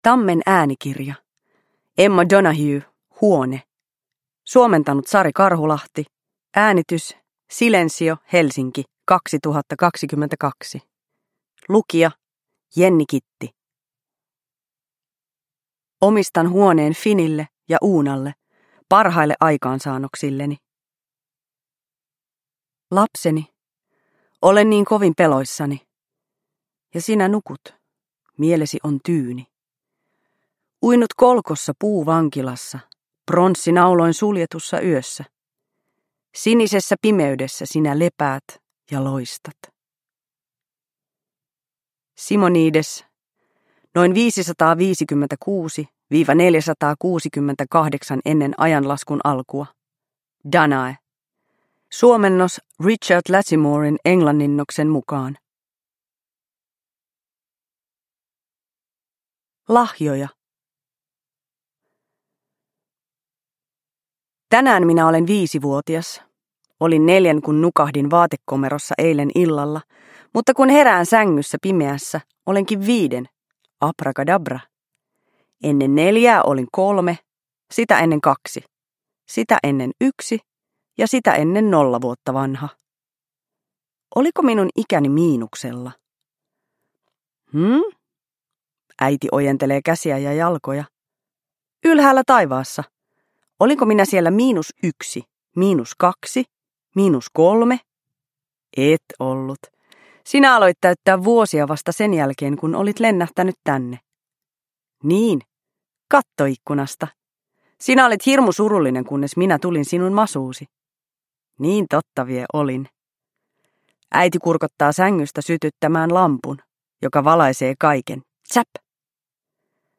Huone – Ljudbok – Laddas ner